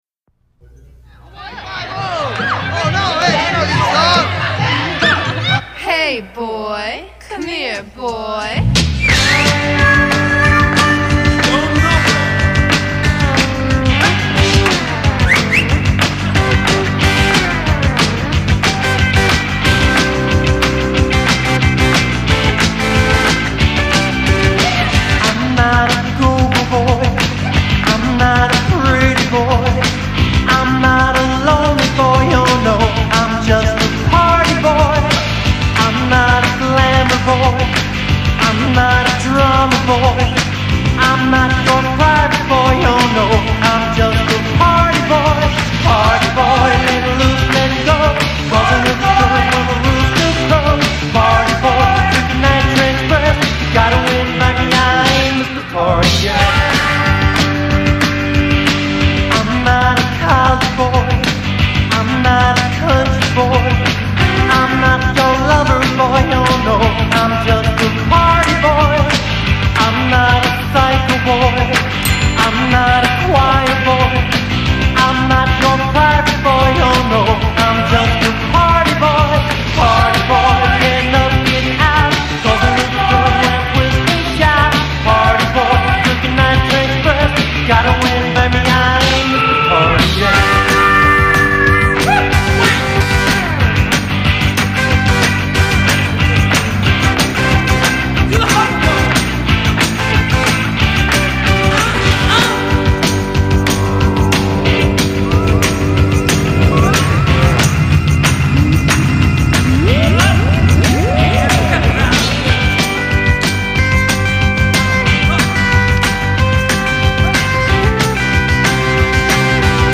vocals
drums
lead guitar
rhythm guitar